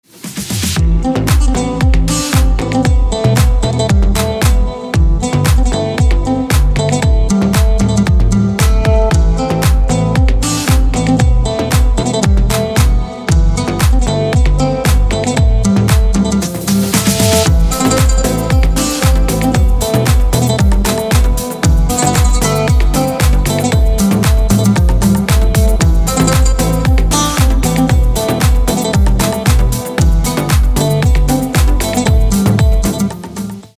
гитара
красивые
deep house
dance
спокойные
без слов
восточные
Tech House